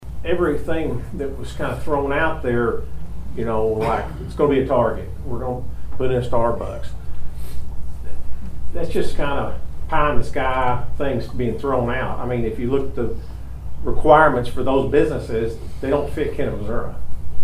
Hearing Held on Proposed Hospital Property Rezoning
hearing-2.mp3